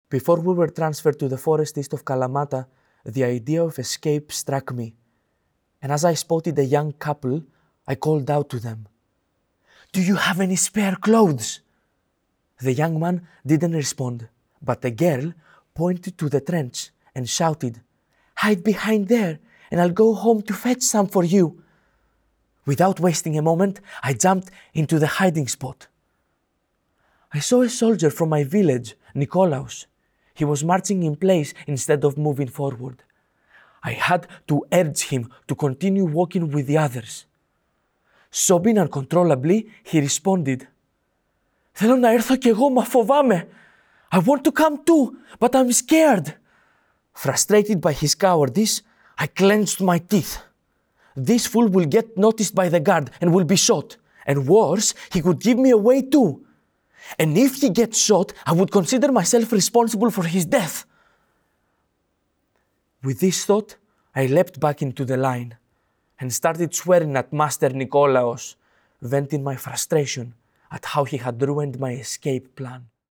Audio dramatisation based on the Memoirs of a Prisoner.